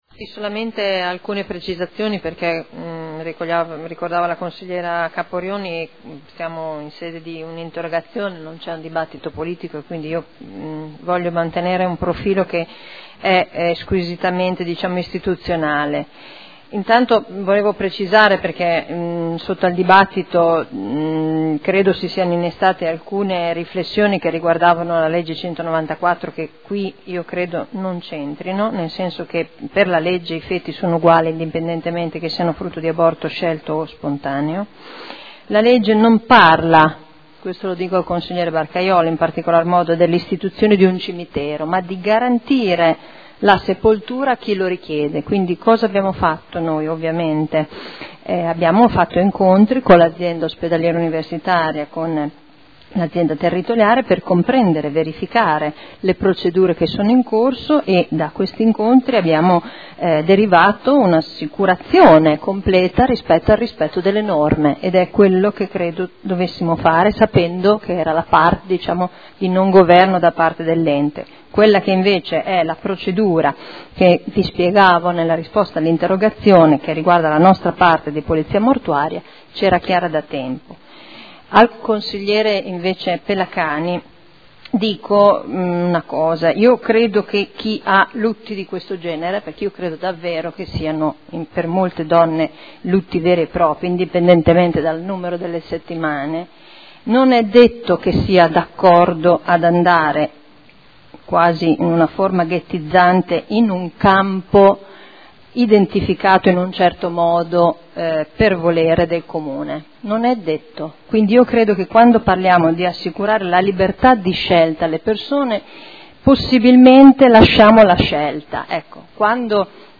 Seduta del 16 gennaio.